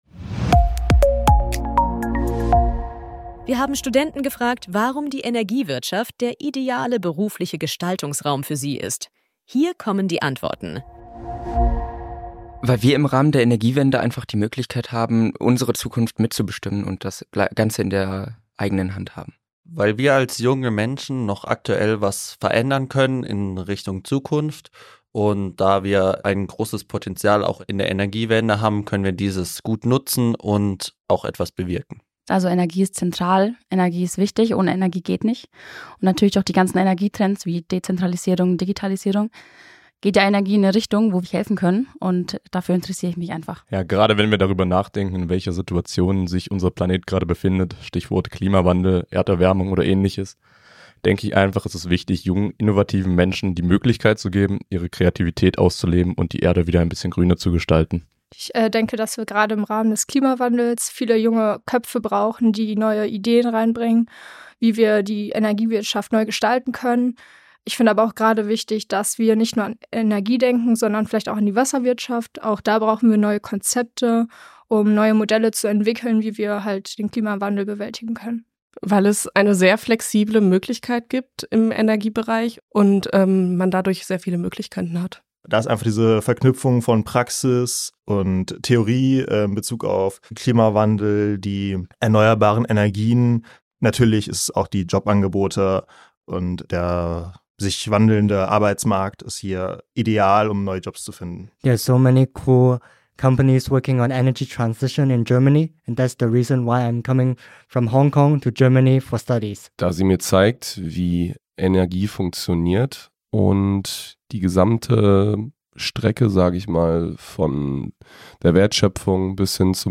Messepodcast zur E-World
Wir haben Studenten gefragt, warum die Energiewirtschaft der ideale